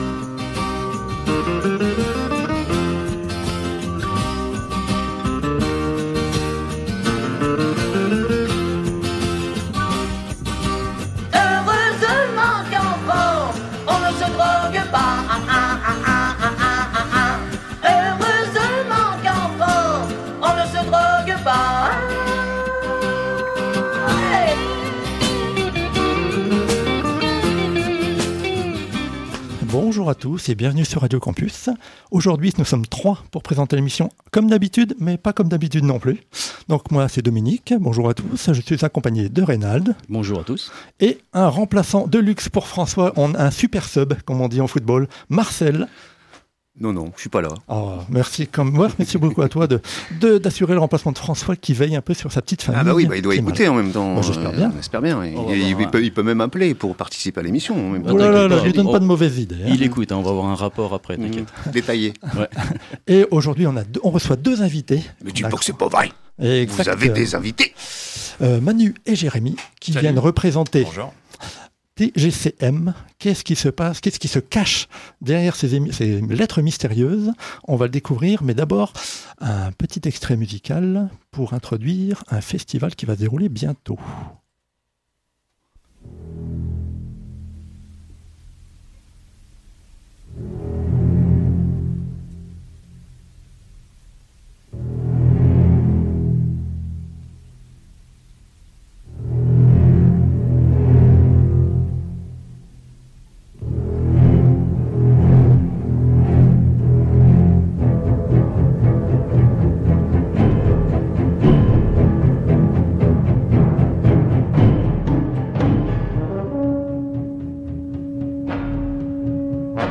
Au sommaire de cet épisode diffusé le 13 septembre 2020 sur Radio Campus 106.6 :